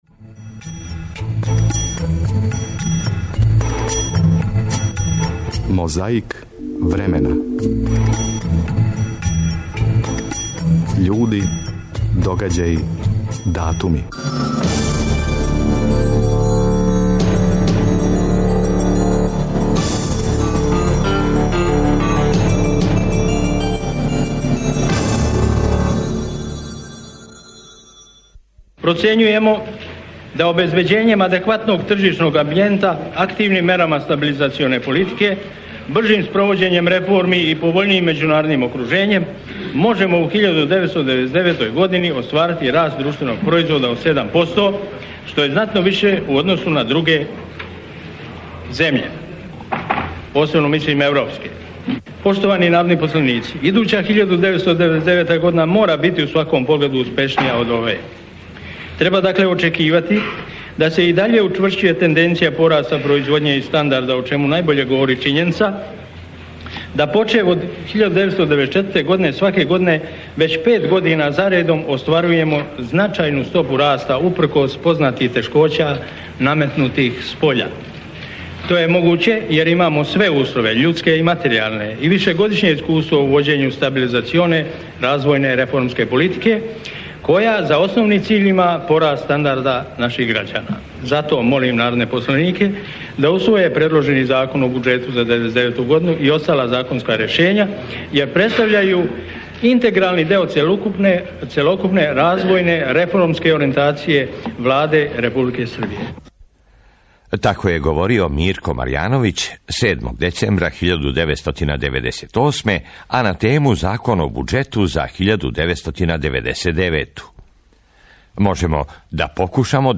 Присећамо се како је говорио Мирко Марјановић на тему Закон о буџету за 1999. годину.
Време уочи избора пре 21 годину: 4. децембра 1990., гост у студију Првог програма Радио Београда био је председнички кандидат СПО-а господин Вук Драшковић.